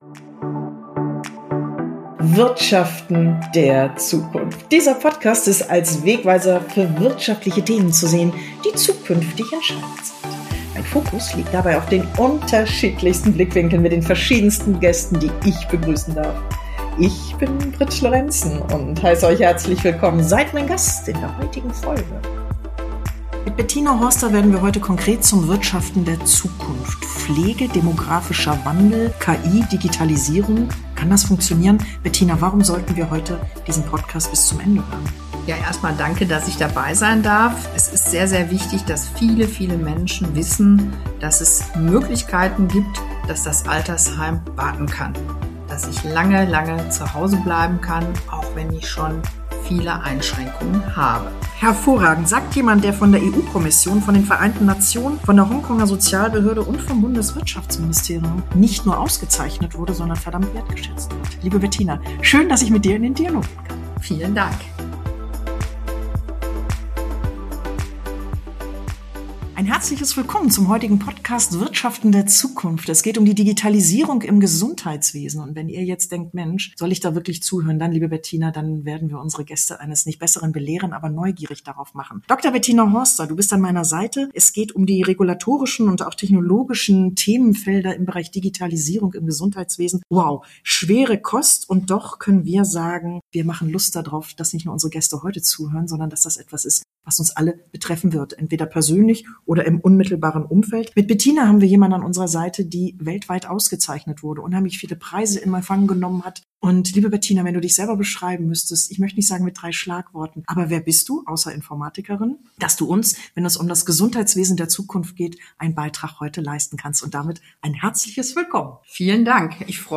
Zwischen Demografie und Digitalisierung: Die Zukunft der Pflege - Im Gespräch